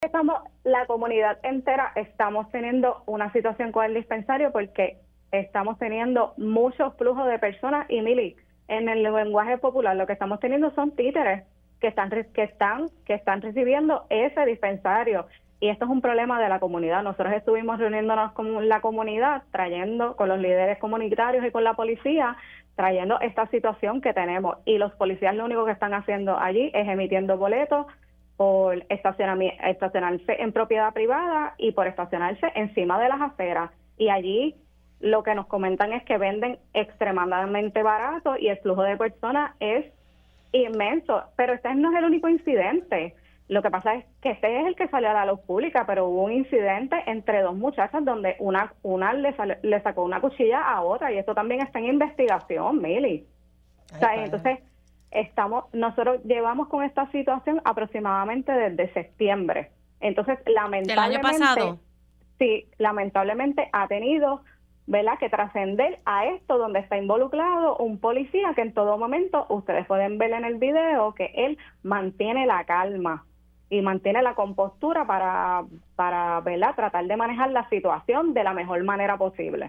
Una mujer, que fue testigo del incidente y reside en la zona, indicó que varias personas “títeres” han provocado situaciones similares en la comunidad debido a un dispensario en el local de Guaynabo que atrae a una gran cantidad de personas con sus bajos precios.